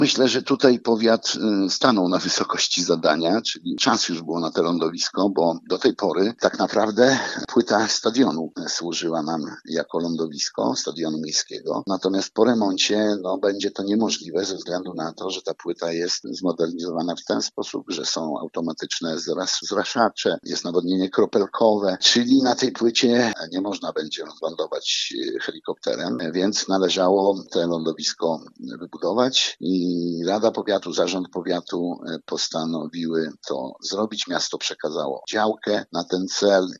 Burmistrz Kolna, Andrzej Duda mówi, że lądowisko było bardzo potrzebne.